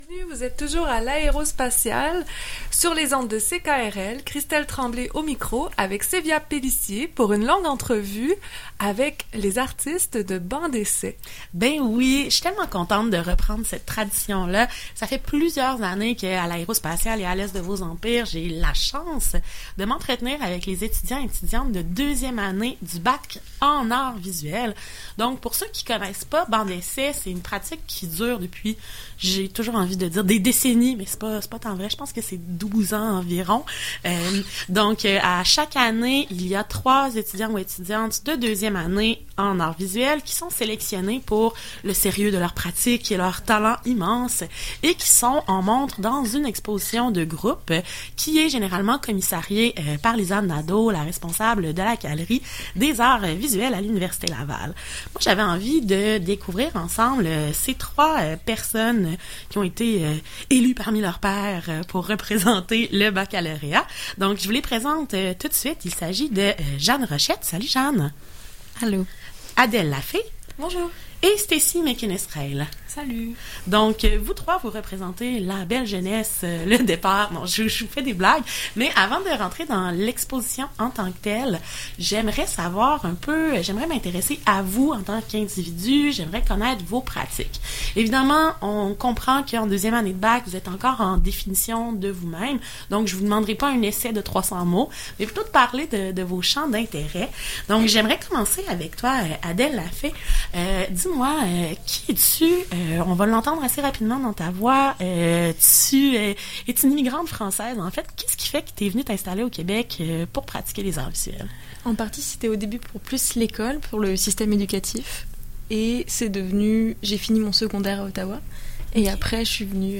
CKRL-entrevue-banc-dessai-2023.mp3